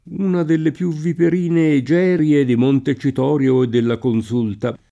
2na delle pL2 vviper&ne eJ$rLe di monte©it0rLo e ddella konS2lta] (Lampedusa) — con e- minusc. come term. zool.